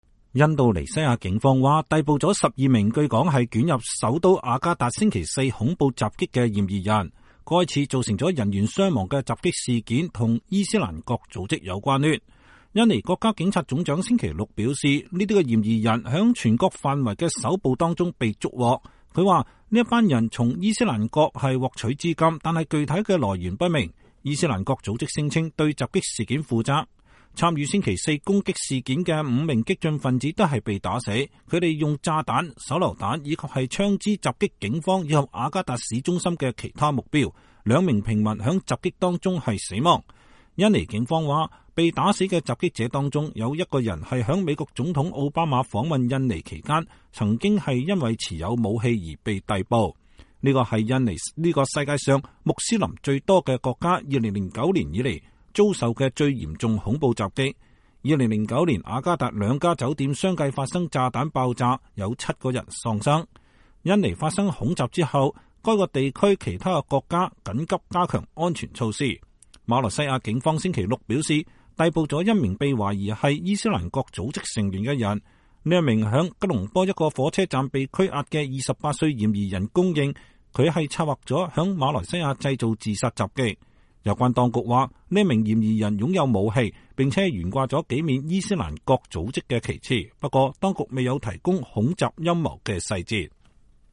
印尼國家警察總長在記者會上宣布逮捕12名涉嫌恐襲嫌疑人。